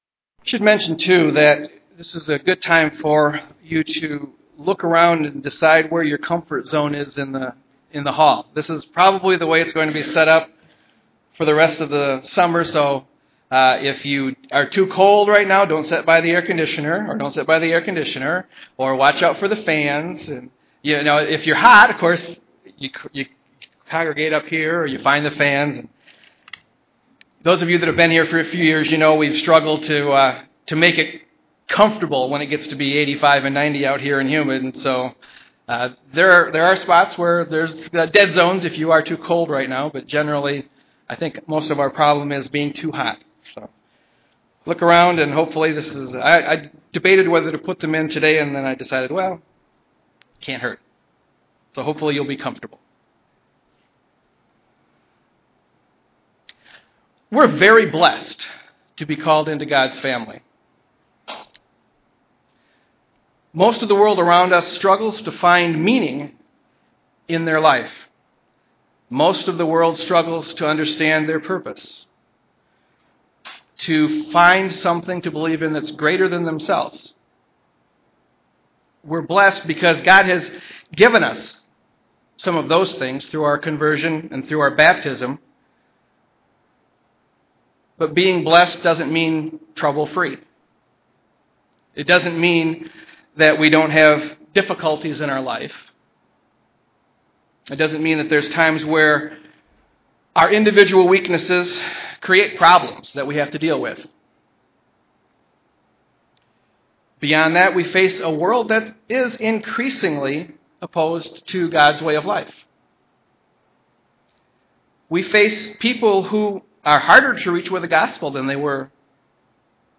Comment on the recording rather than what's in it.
Given in Grand Rapids, MI